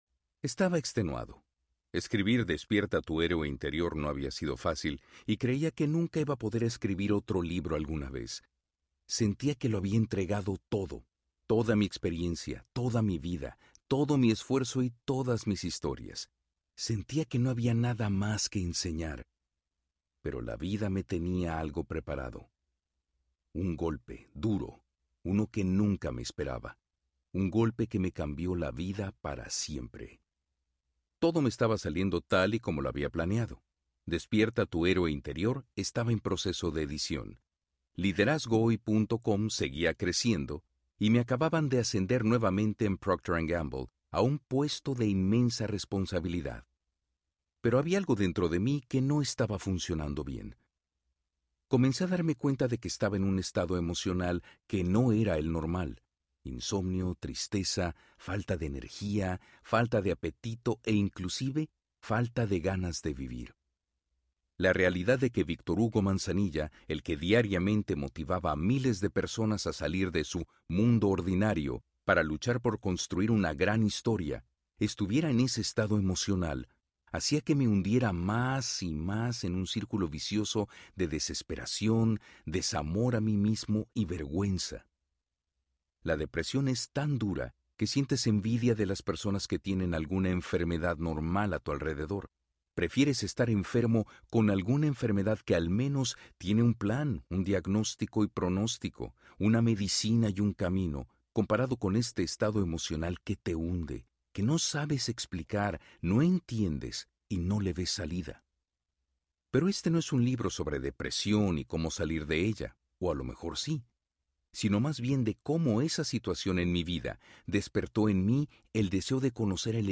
Tu momento es ahora Audiobook
Narrator
6.4 Hrs. – Unabridged